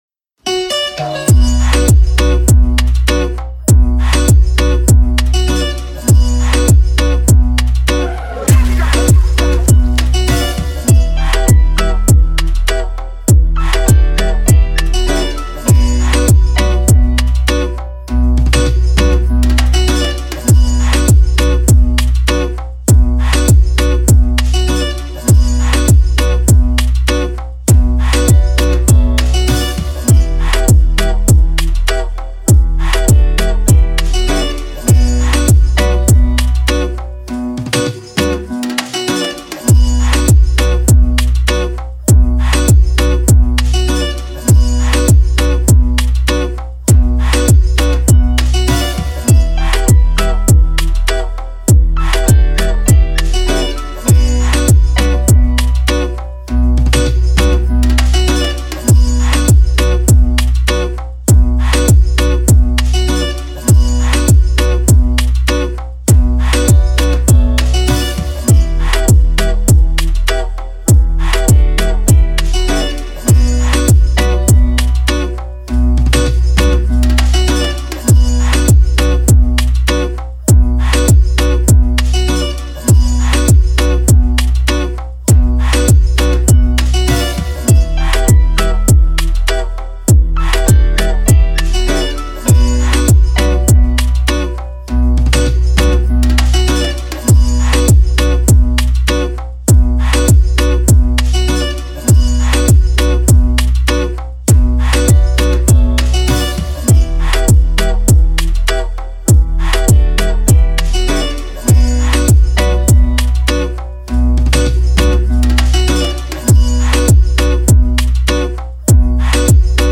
Reggae Instrumentals